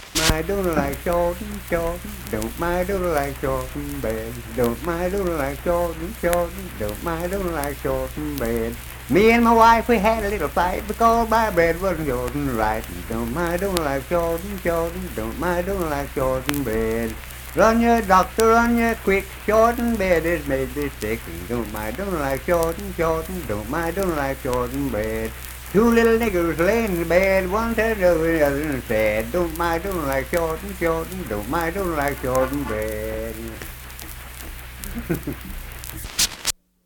Unaccompanied vocal and banjo music
Verse-refrain 4(4w/R).
Minstrel, Blackface, and African-American Songs
Voice (sung)
Clay County (W. Va.), Clay (W. Va.)